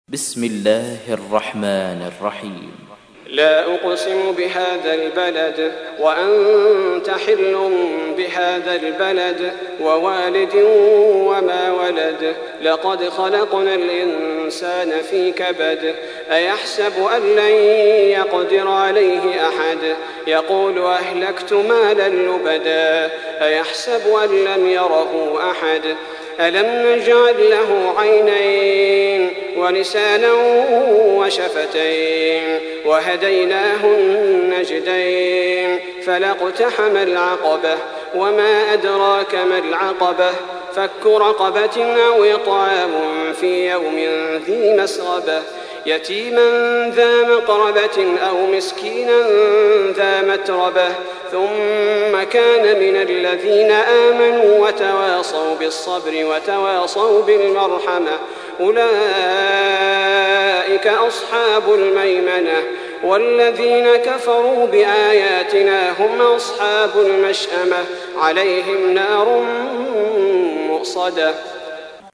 تحميل : 90. سورة البلد / القارئ صلاح البدير / القرآن الكريم / موقع يا حسين